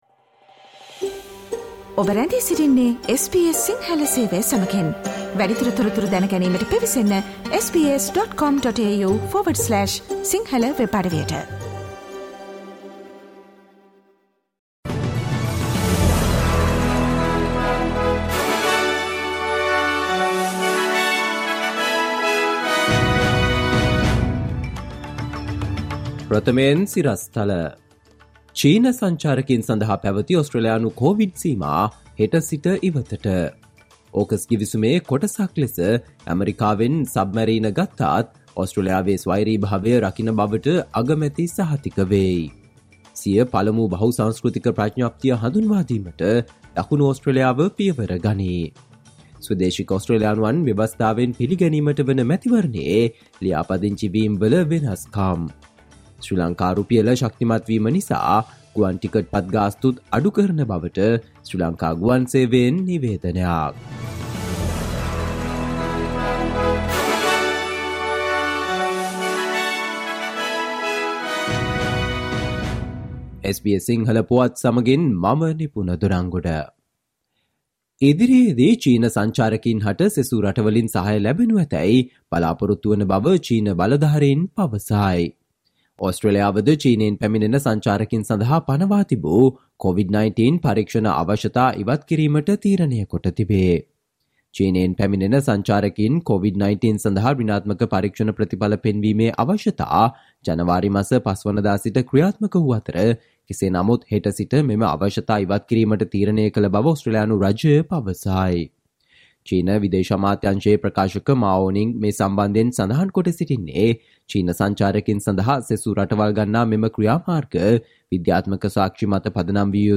ඕස්ට්‍රේලියාවේ නවතම පුවත් රැගත් SBS සිංහල සේවයේ 2023 මාර්තු 10 වන දා සිකුරාදා වැඩසටහනේ ප්‍රවෘත්ති ප්‍රකාශයට සවන් දෙන්න.